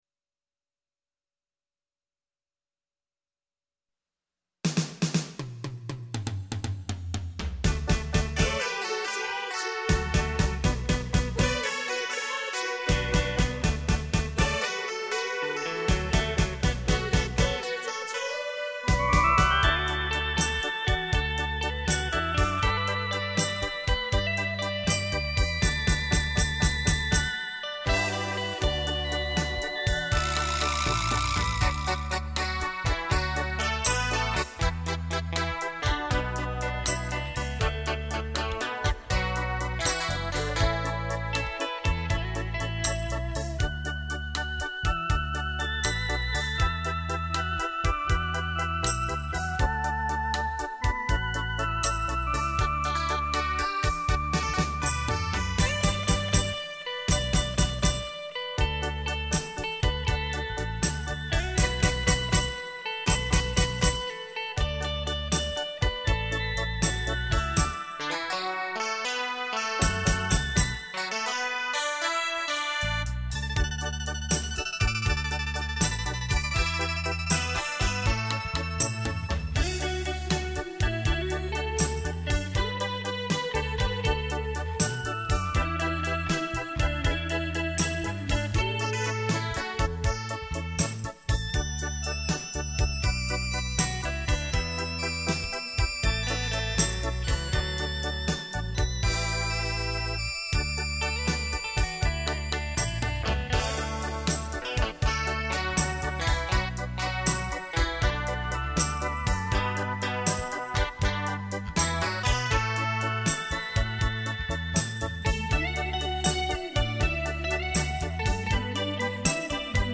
演奏乐器：电子琴